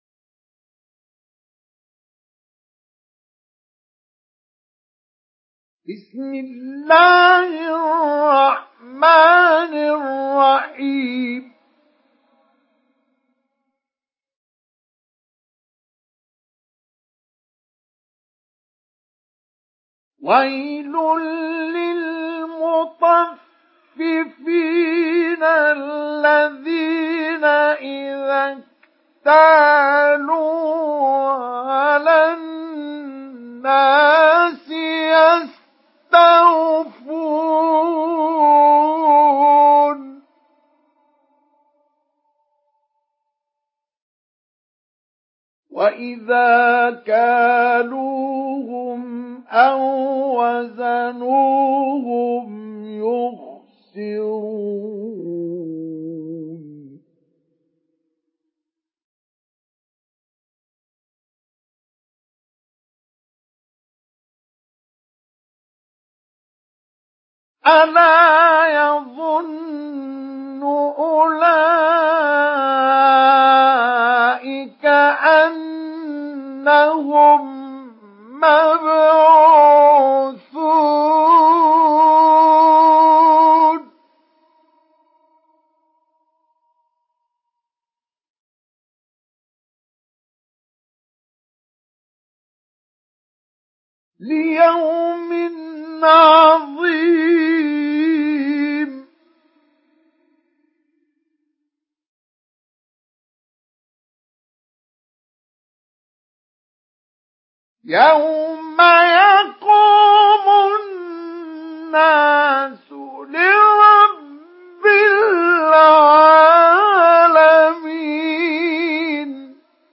Surah Al-Mutaffifin MP3 by Mustafa Ismail Mujawwad in Hafs An Asim narration.